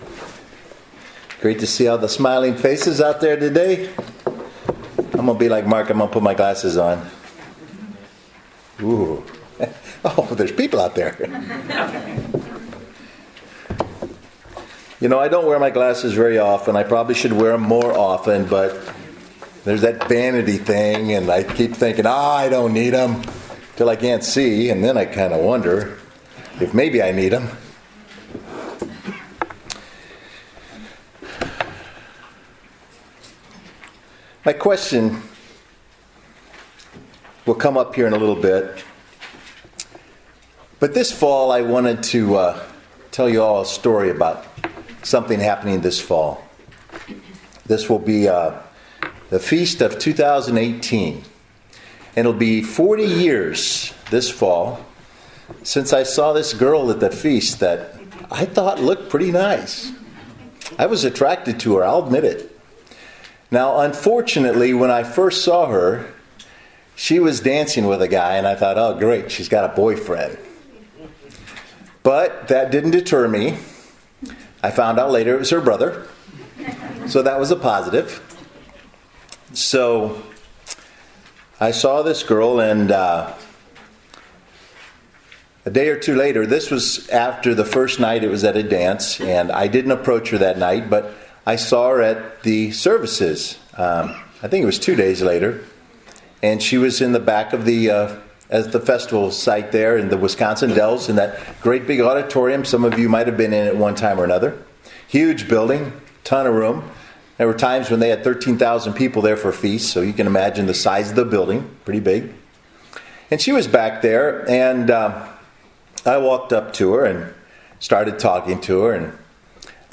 Given in Murfreesboro, TN